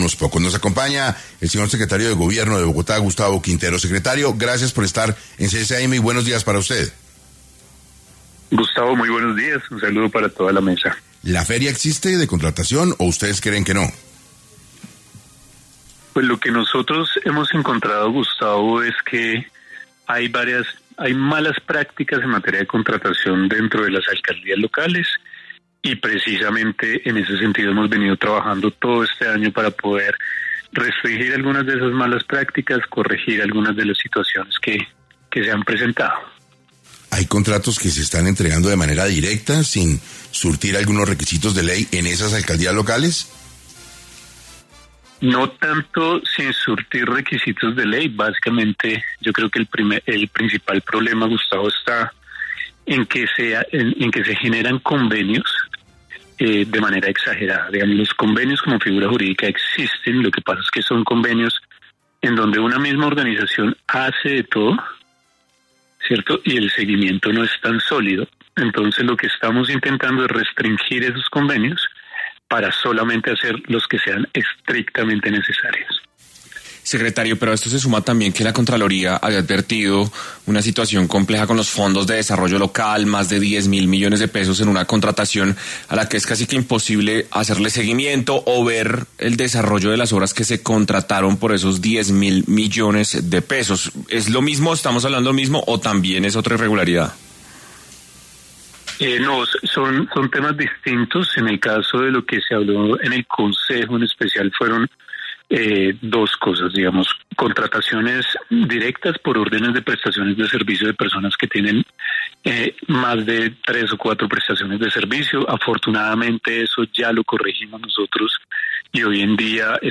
El secretario de Gobierno de Bogotá, Gustavo Quintero explicó en 6AM que hay malas prácticas en manera de contratación dentro de las Alcaldías Locales.